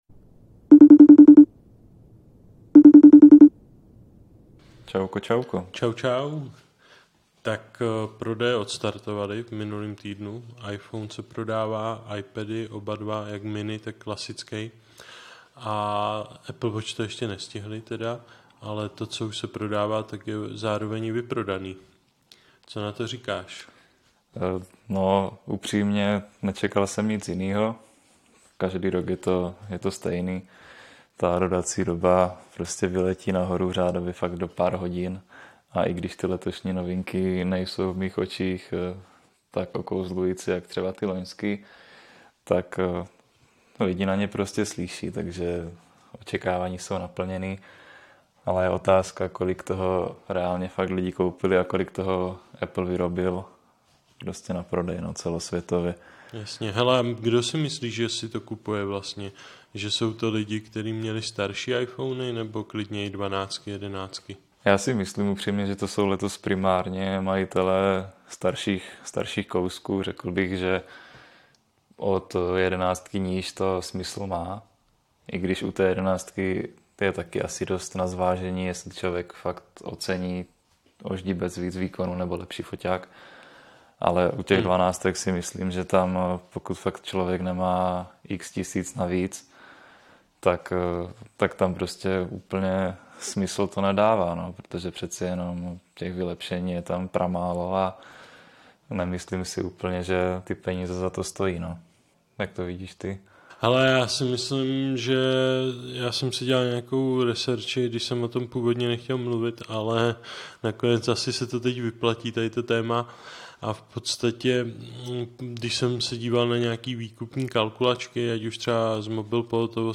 Páteční podcast přes FaceTime vol. 16: První recenze na iPhony 13 i očekávání do konce roku